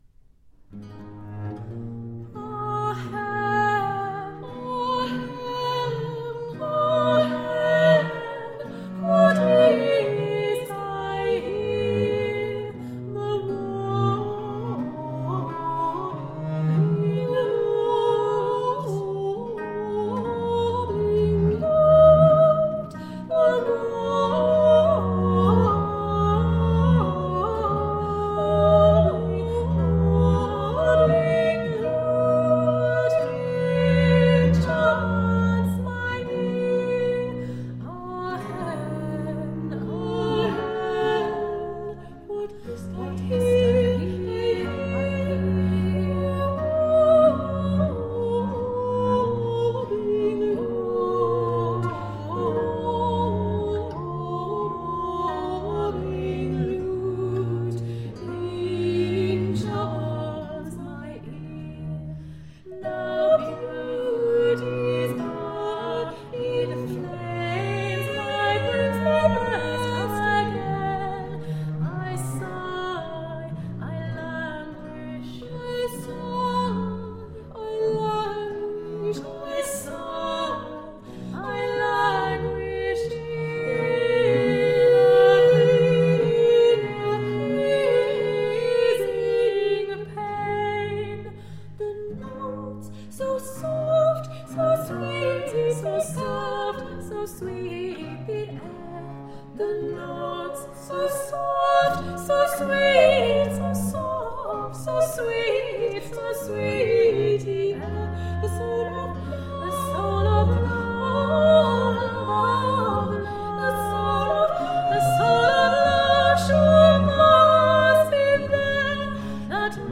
Traditional early english music.